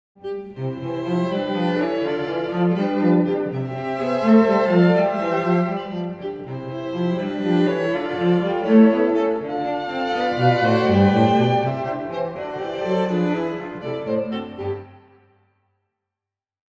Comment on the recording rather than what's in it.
which mixed 2 channels of down with a stereo.